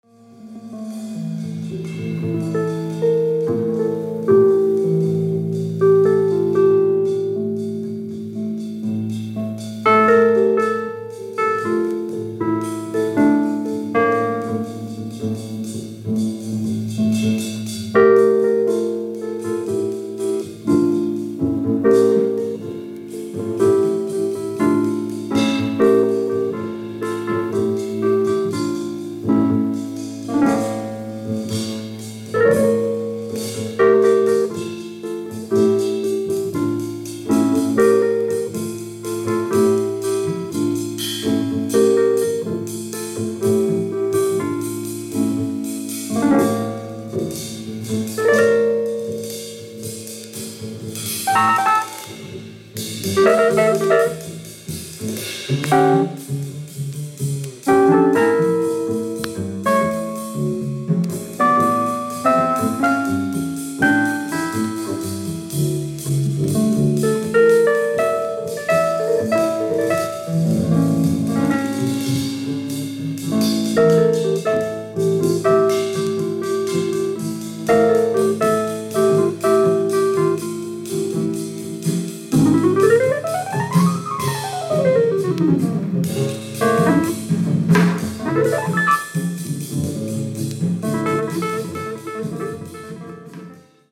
鍵盤奏者
これからの季節にのんびりどうぞ。